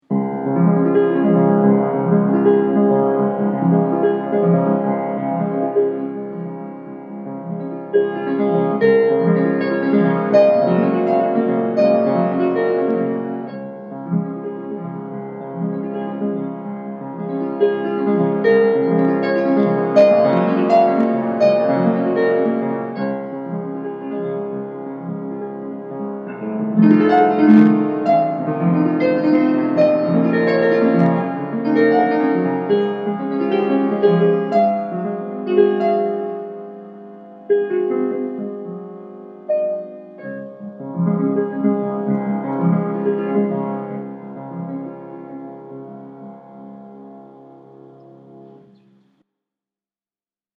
Pianiste